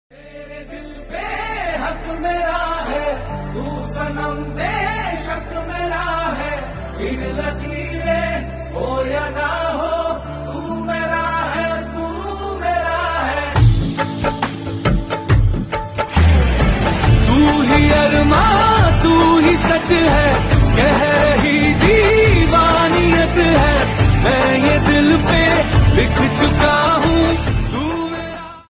soulful and romantic tune